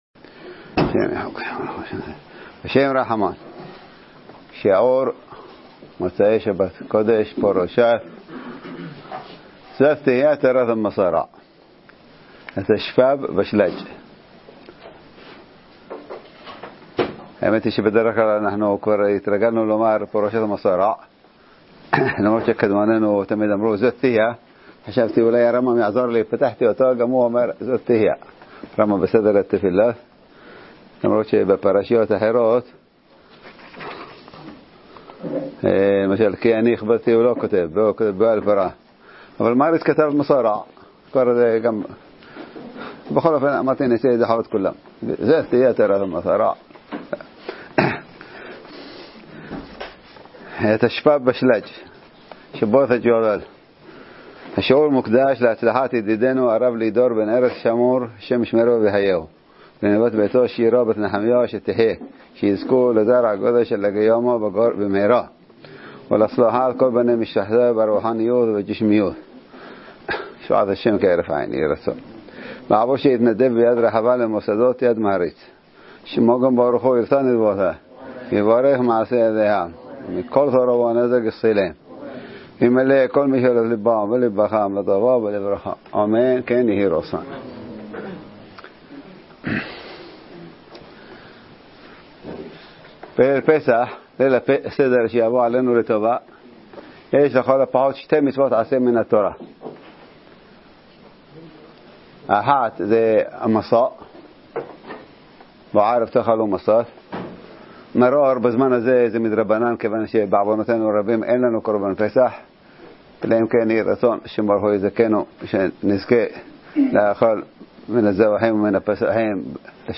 מוצש"ק מצורע - דרשת שבת הגדול התשפ"ב